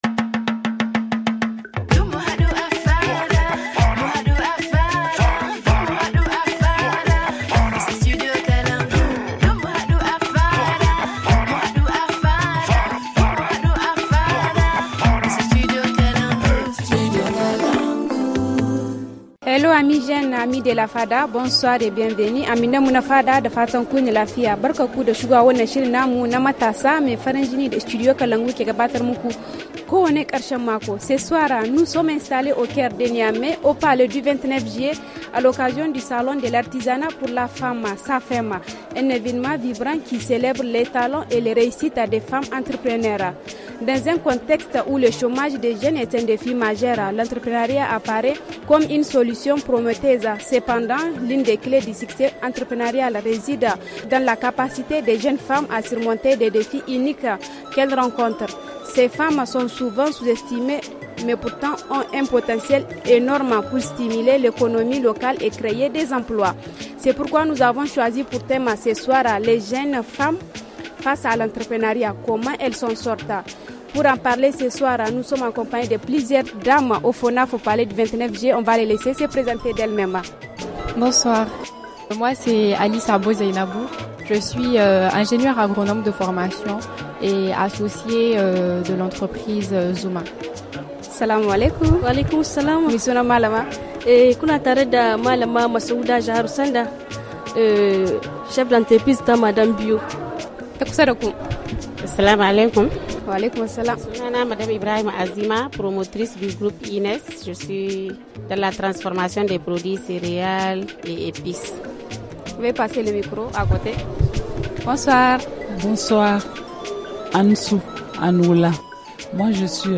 Ce soir, en direct du Palais du 29 Juillet à Niamey, nous sommes au cœur du Salon de l’Artisanat pour la Femme (SAFEM), un événement dédié à la célébration des talents et réussites des femmes entrepreneures.